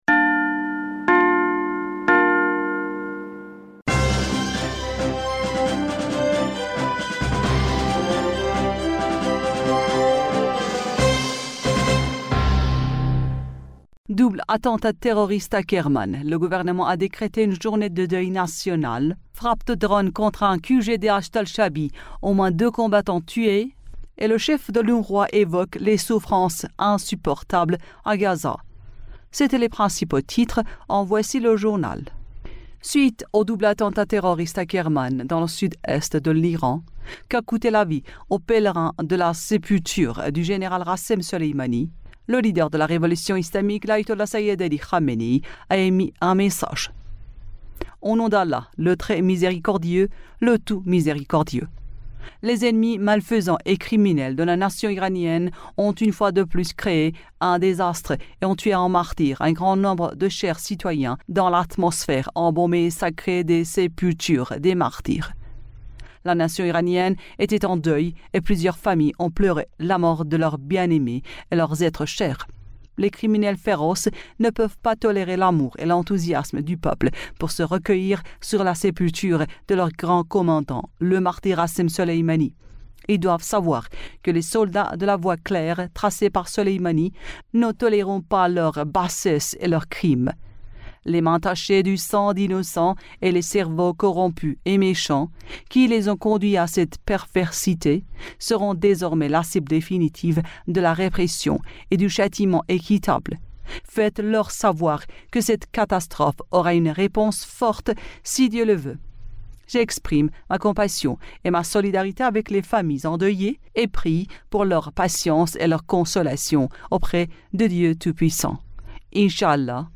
Bulletin d'information du 04 Janvier 2024